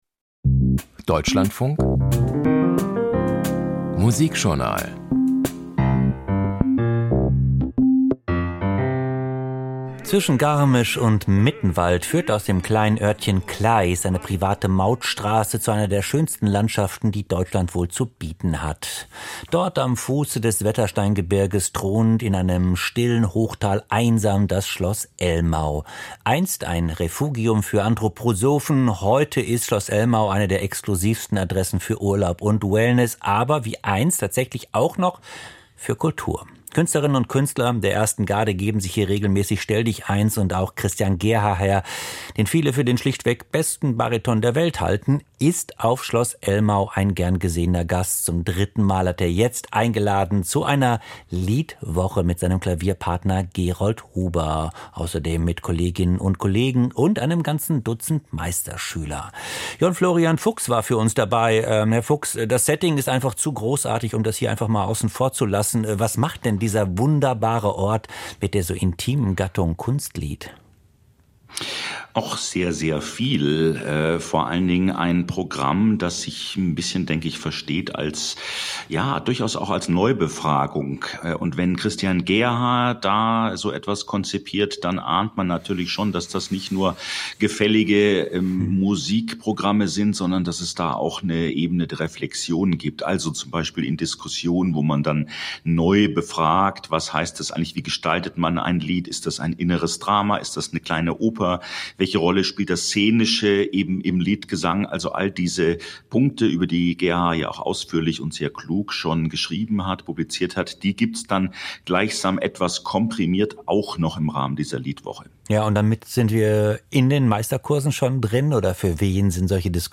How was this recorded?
Lied Festival Christian Gerhaher & Gerold Huber Schloss Elmau, Bavaria, Germany